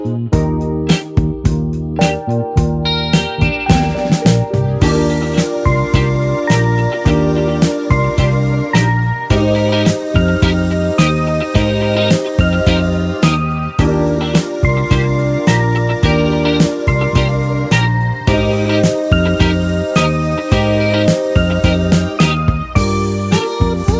no Backing Vocals Soul / Motown 4:08 Buy £1.50